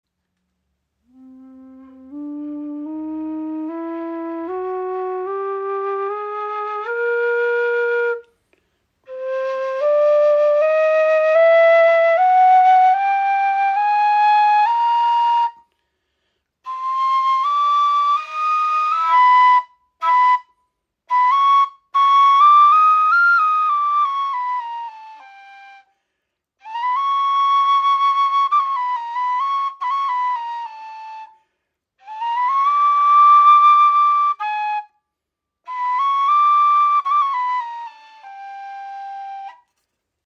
Kaval / Neyval | 7 Grifflöcher | C
Kaval oder Neyval sind Hirtenflöten, die in der Volksmusik der Türkei und des Balkans vorkommen.
Die Halbtonschritte in ihrer Stimmung geben ihr einen orientalischen oder Balkan Charakter.
Stimmung: C - D Eb E F# G Ab Bb
In den sanften Schwingungen der Kaval liegt ein zarter Klang, wie seidiger Nebel, der sich um die Sinne webt.